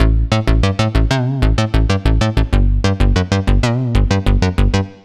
AM_OB-Bass_95-A.wav